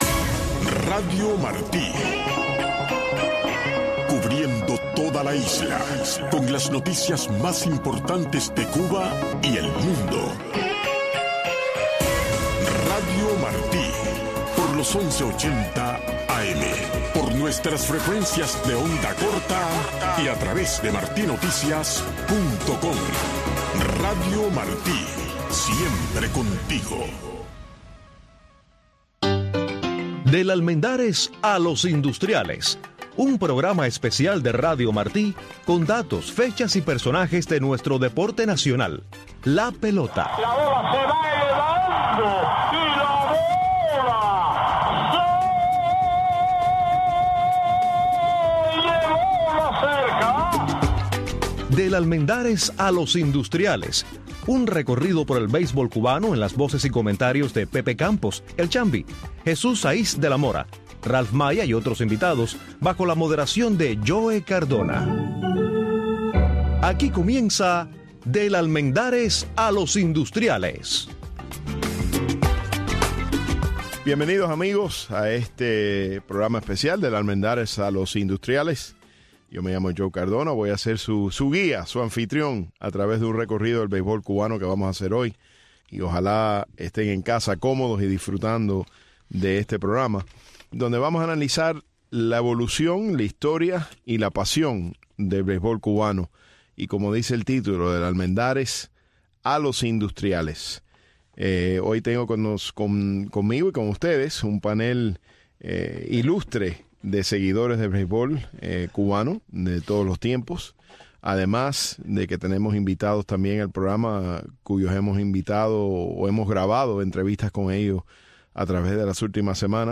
Del Almendares a Los Industriales es un recuento y celebración del pasatiempo nacional cubano, la pelota. Escritores, cronistas deportivos, fanáticos y ex peloteros analizan y comentan sobre el deporte y su colorida historia en Cuba.